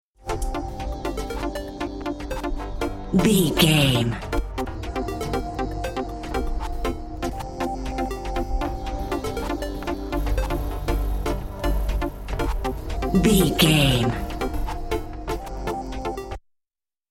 Modern EDM Stinger.
Aeolian/Minor
groovy
uplifting
futuristic
driving
energetic
repetitive
synthesiser
drum machine
house
techno
trance
synth leads
synth bass
upbeat